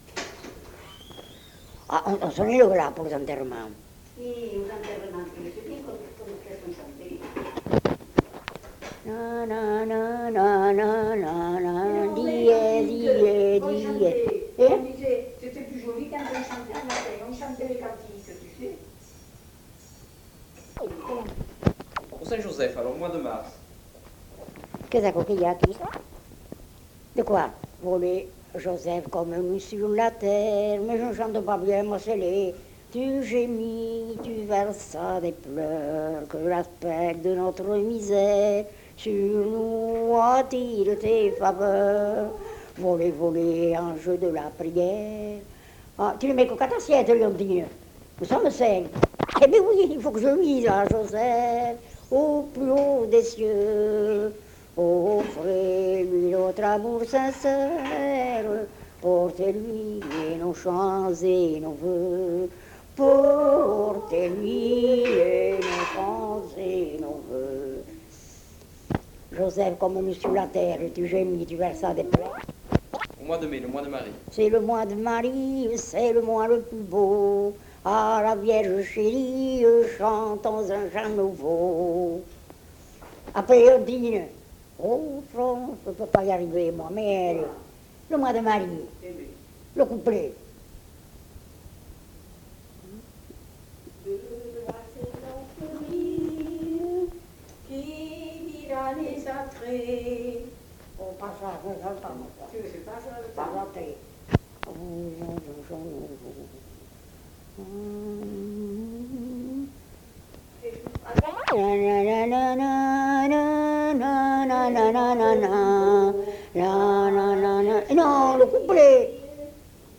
Sur divers cantiques (avec fragments de chants)
Lieu : Mas-Cabardès
Genre : parole